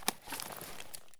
draw_pistol.ogg